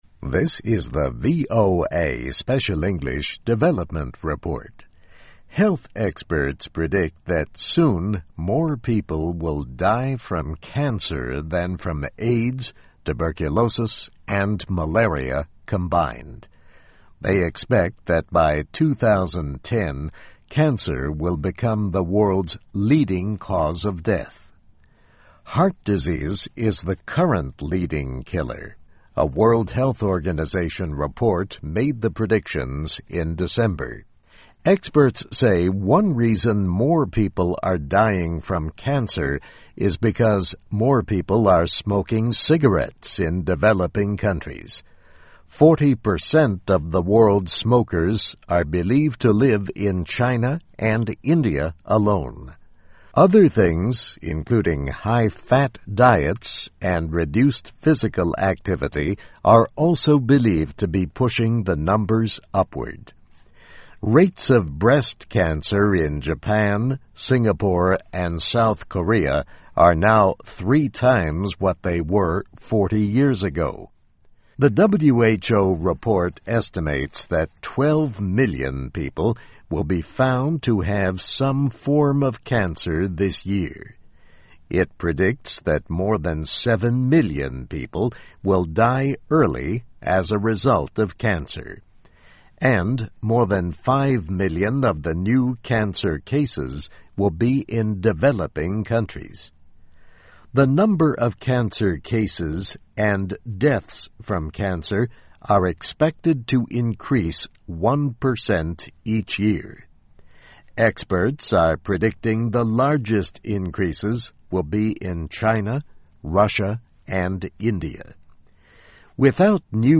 Medical: Cancer May Soon Be World's Leading Killer (VOA Special English 2009-03-01)